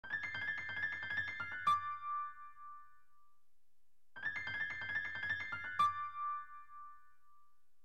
Jazz - Blues
Blues